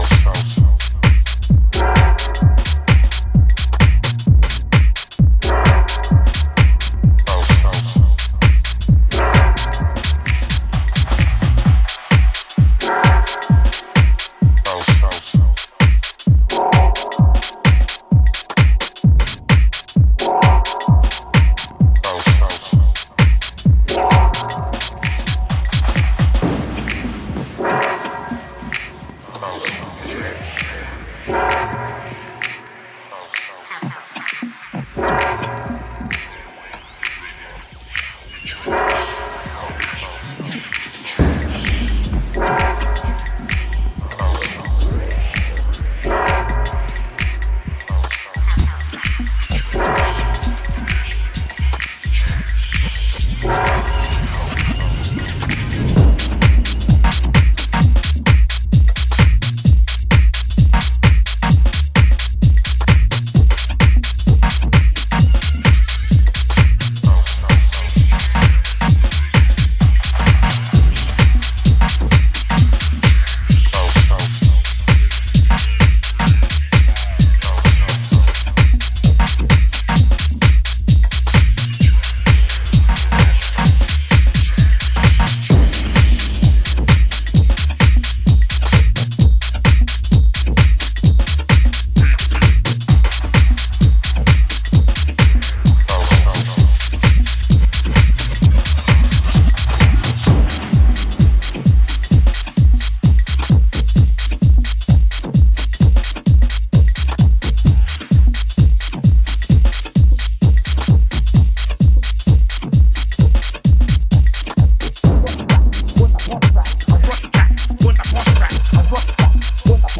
本作では、ヘヴィなボトムと分厚いベースラインで爆走する、ダークでパワフルなミニマル・テック・ハウスを展開。